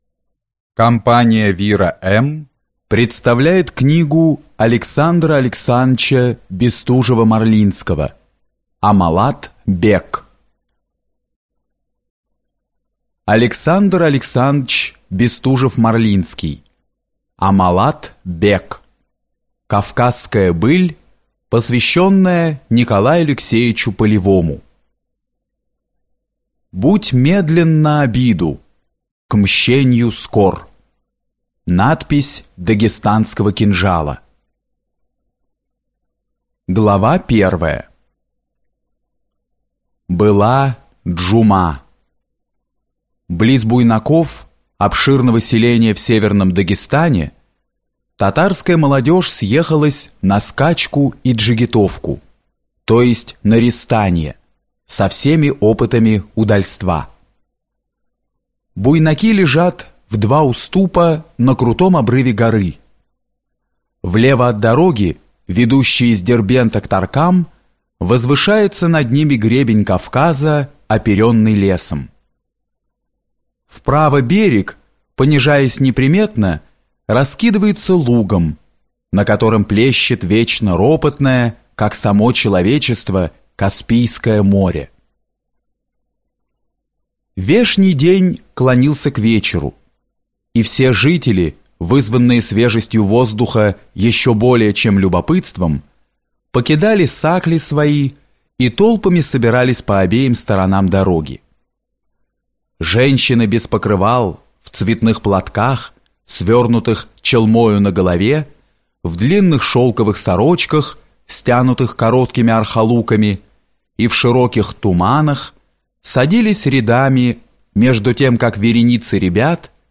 Аудиокнига Аммалат-бек | Библиотека аудиокниг